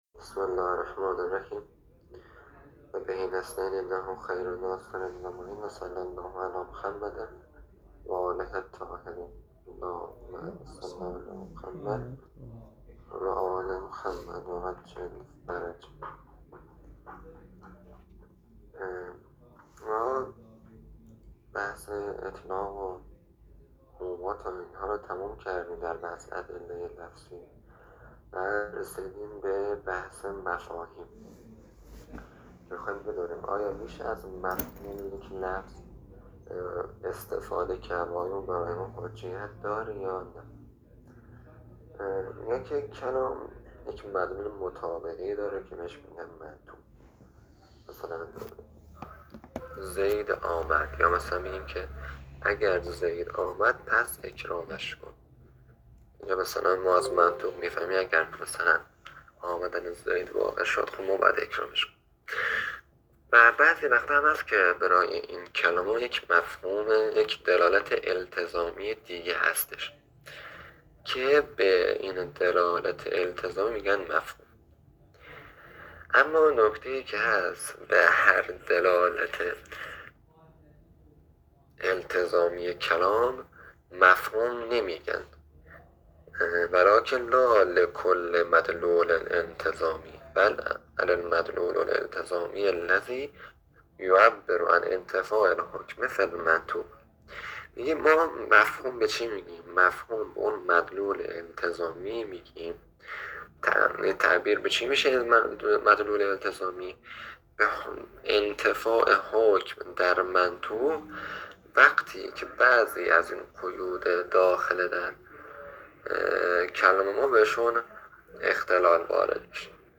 تدریس كتاب حلقه ثانیه